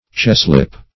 cheslip - definition of cheslip - synonyms, pronunciation, spelling from Free Dictionary
cheslip - definition of cheslip - synonyms, pronunciation, spelling from Free Dictionary Search Result for " cheslip" : The Collaborative International Dictionary of English v.0.48: Cheslip \Ches"lip\, n. (Zool.)